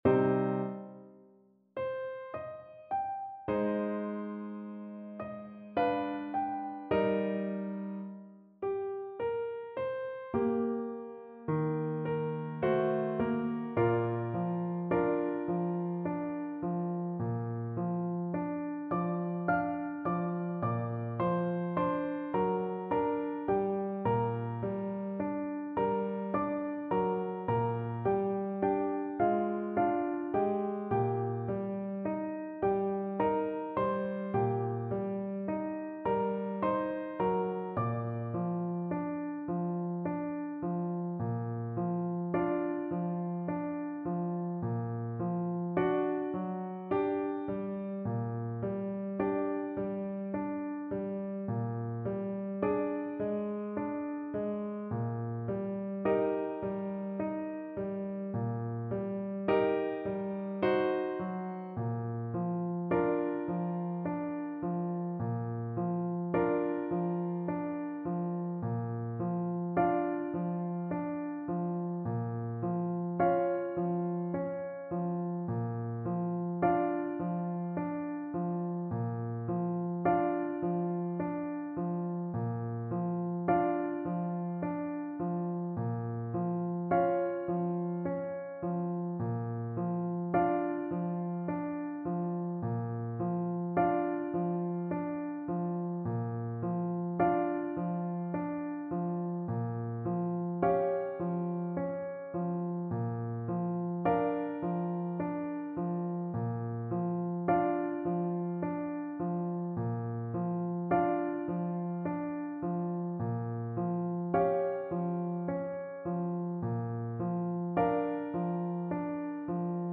Clarinet version
3/4 (View more 3/4 Music)
Andantino =70 (View more music marked Andantino)
Classical (View more Classical Clarinet Music)